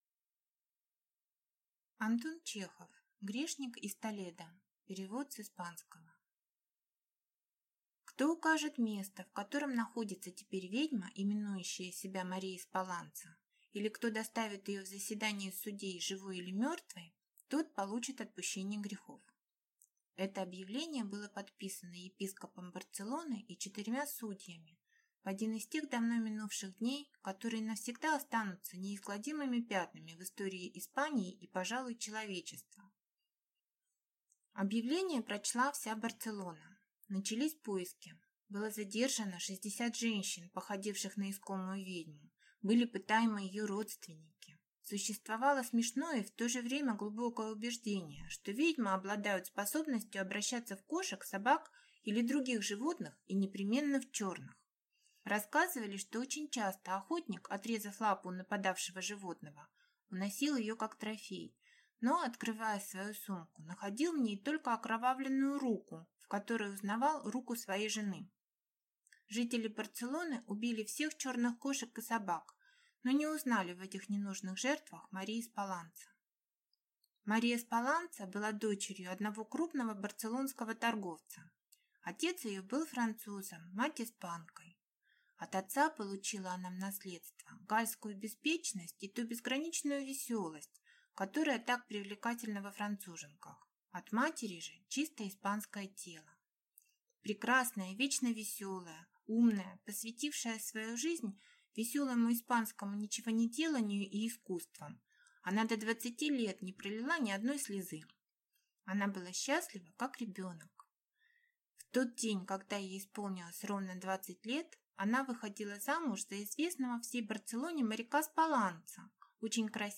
Аудиокнига Грешник из Толедо | Библиотека аудиокниг